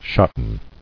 [shot·ten]